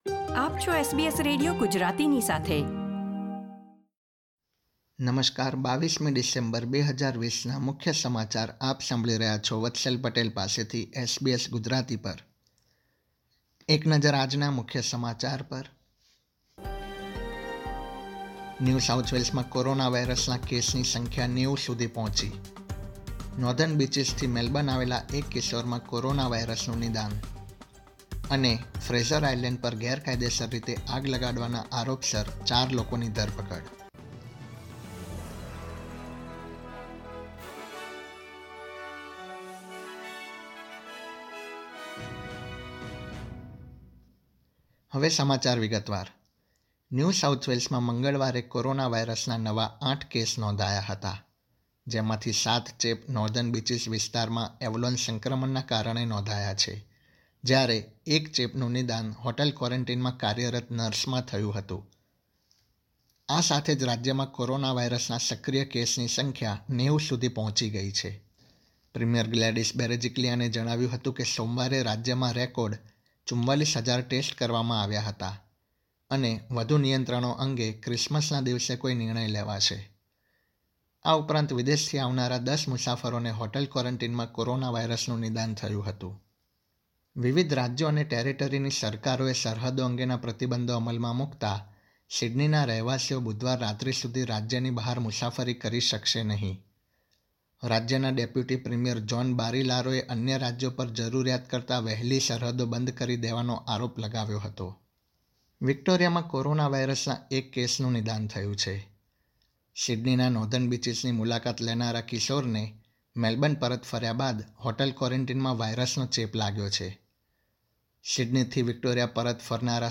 SBS Gujarati News Bulletin 22 December 2020
gujarati_2212_newsbulletin.mp3